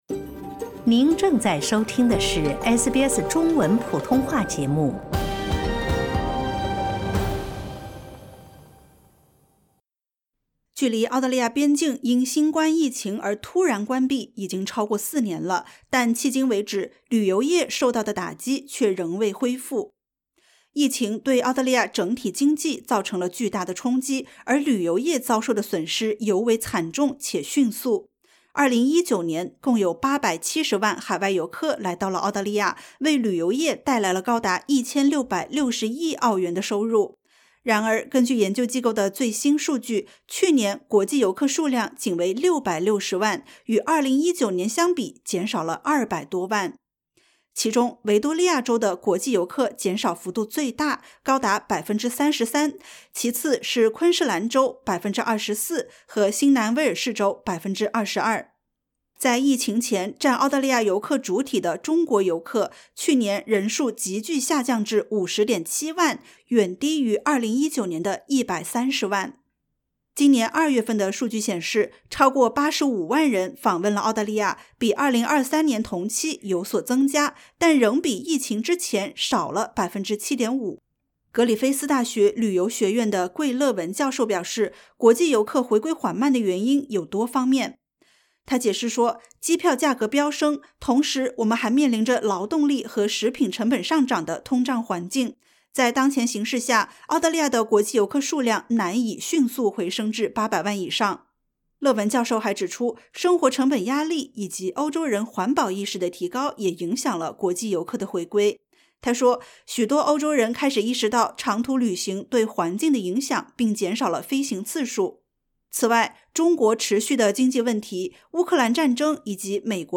澳大利亚旅游业受新冠疫情影响严重，复苏步伐缓慢，预计需数年才能全面恢复。点击 ▶ 收听完整采访。